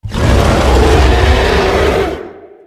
wolf_attack_1.ogg